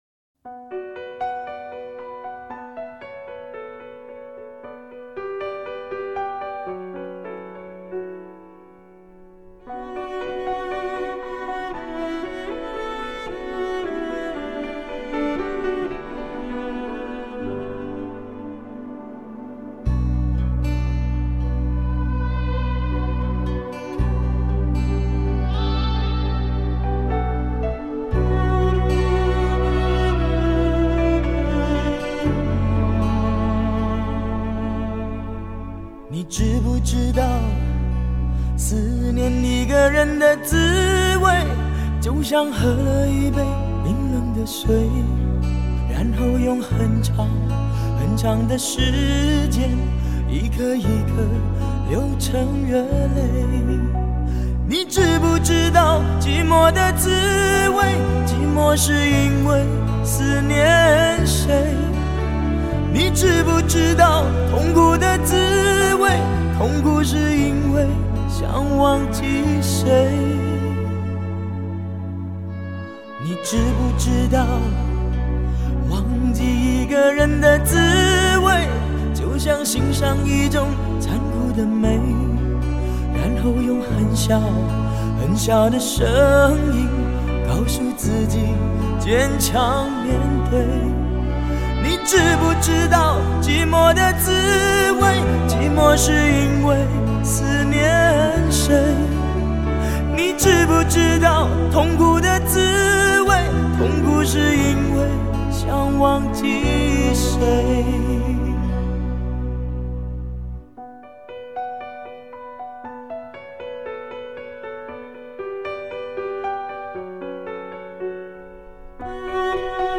绝世伤感老情歌 演绎世间男女不解情缘 献给天下有情人
不折不扣的实力派唱将